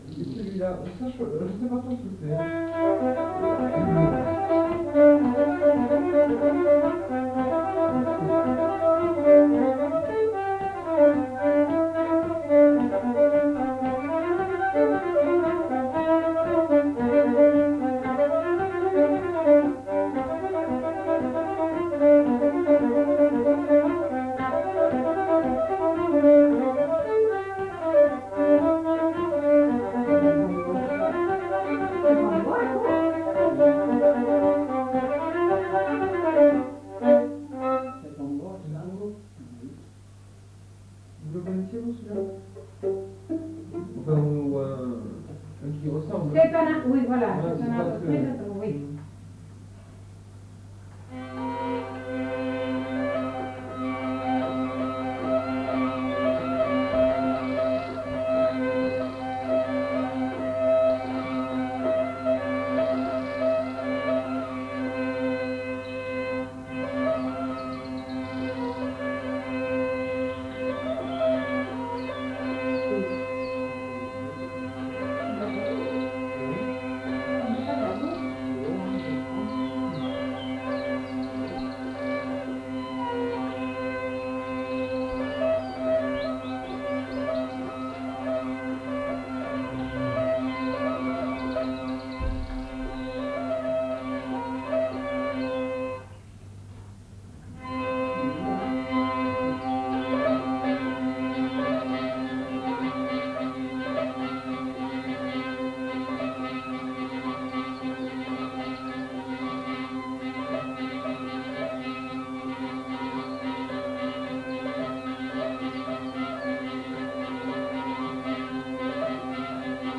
Aire culturelle : Petites-Landes
Genre : morceau instrumental
Instrument de musique : vielle à roue ; violon
Danse : congo
Notes consultables : Le collecteur propose une mélodie mais l'interprète en joue une autre.